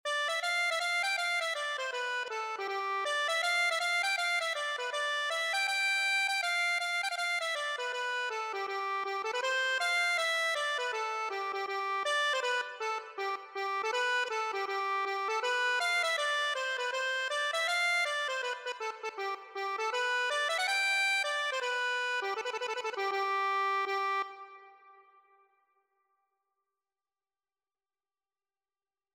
G major (Sounding Pitch) (View more G major Music for Accordion )
4/4 (View more 4/4 Music)
G5-G6
Accordion  (View more Easy Accordion Music)
Traditional (View more Traditional Accordion Music)